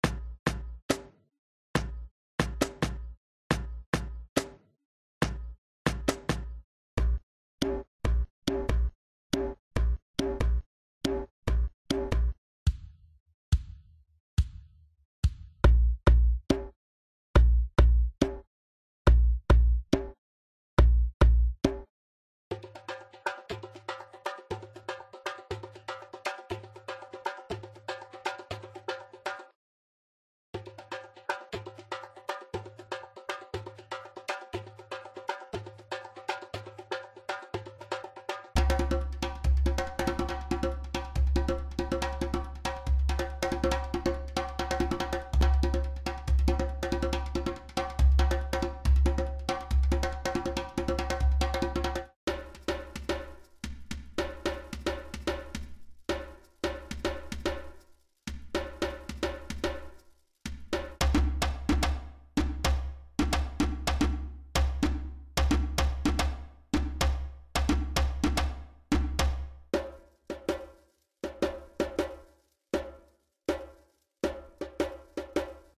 Звук пения монстра